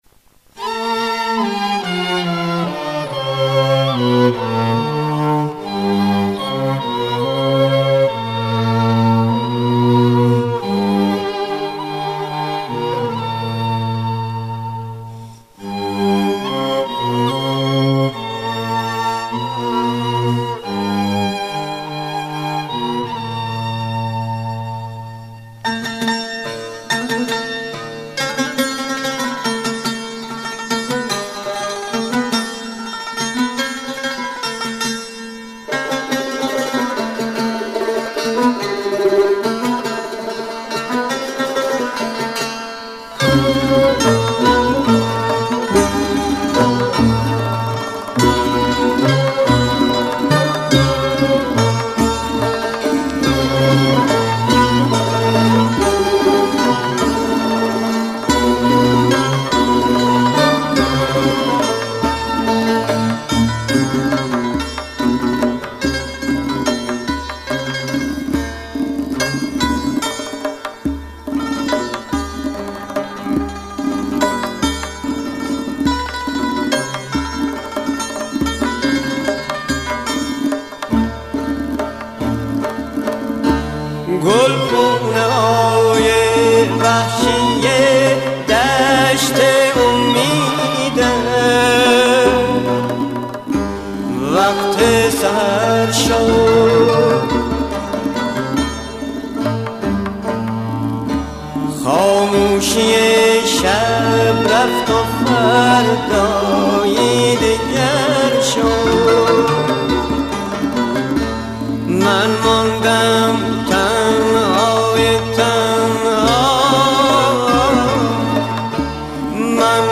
Таснифи